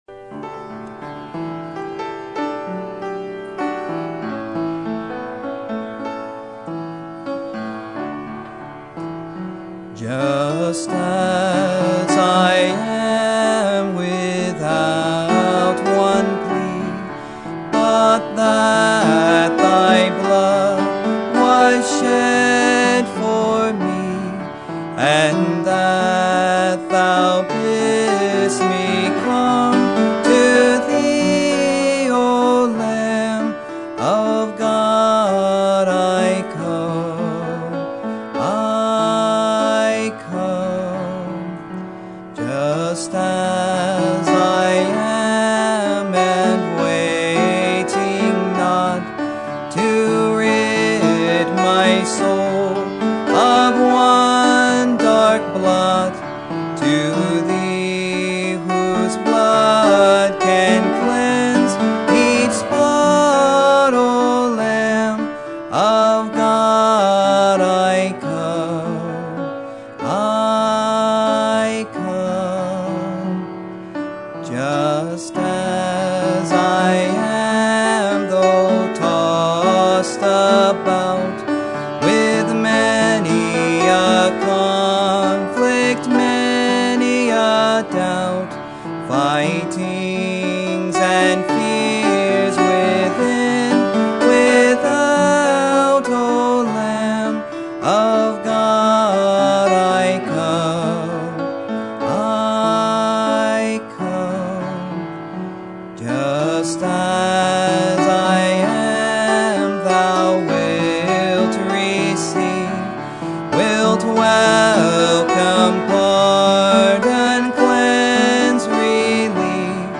Sermon Topic: General Sermon Type: Service Sermon Audio: Sermon download: Download (27.33 MB) Sermon Tags: Philippians Self Revival Mindset